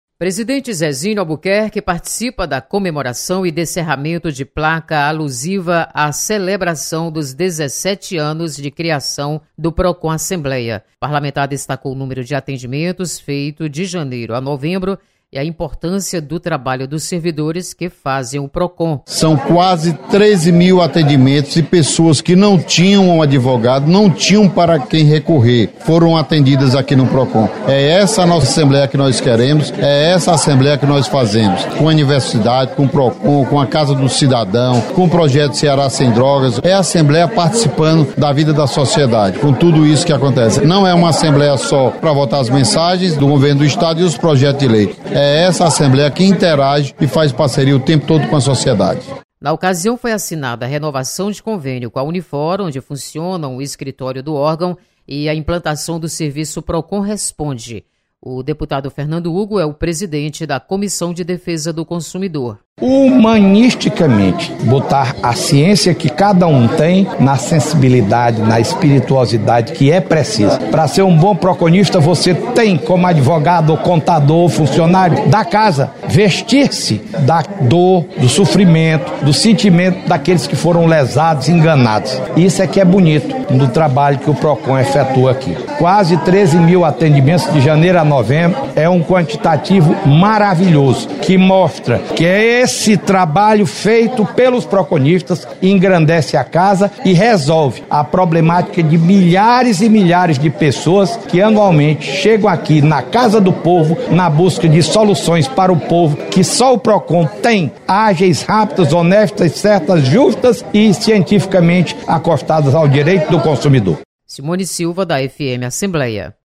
Presidente Zezinho Albuquerque comemora 17 anos de atuação do Procon Assembleia.